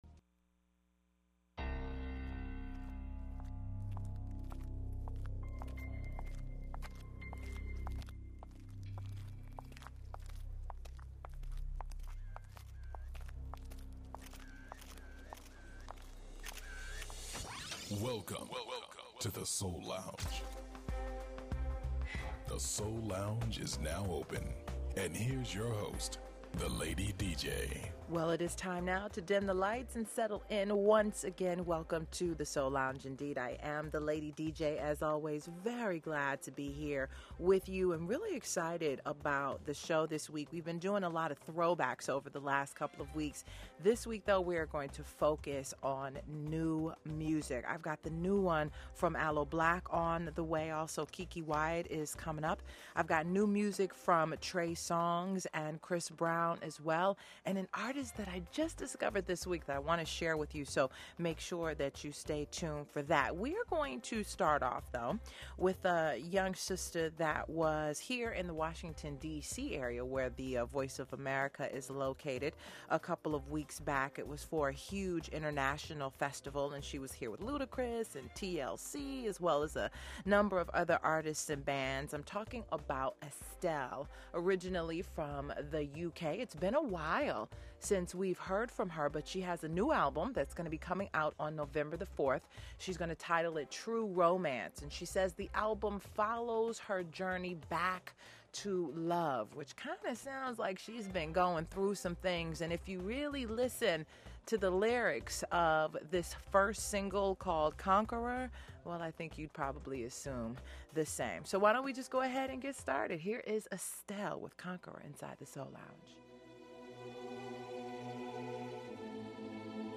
You’ll also hear Classic Soul from legendary musicians who have inspired a the new generation of groundbreaking artists.